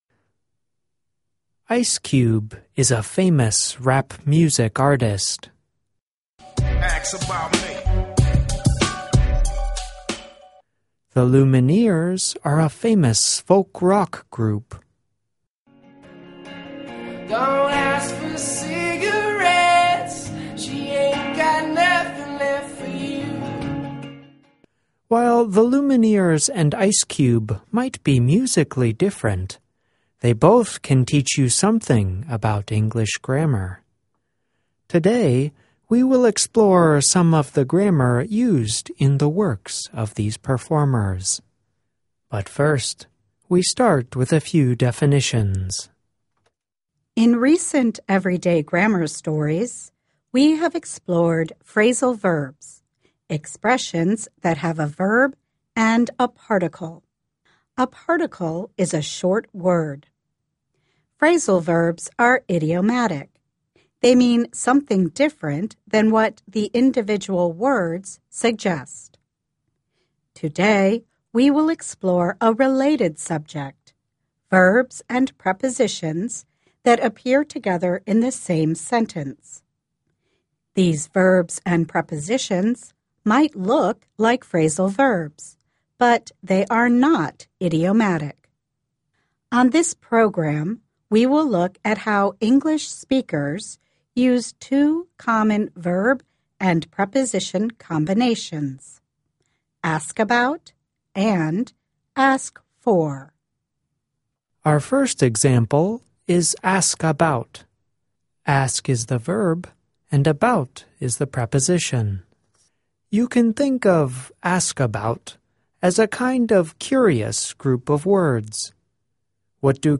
Gramática inglesa en audio. Verbs and Prepositions